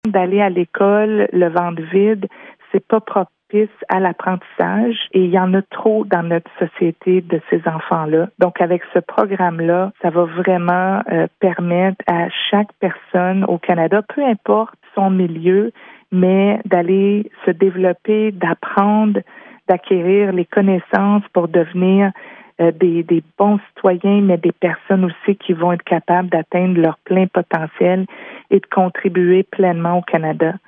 Ottawa va verser 65,2 millions de dollars pendant les trois prochaines années pour soutenir les programmes d’alimentation en milieu scolaire du gouvernement du Québec, afin de lutter contre l’insécurité alimentaire. La députée fédérale de Pontiac-Kitigan Zibi, Sophie Chatel, s’exprime sur le sujet :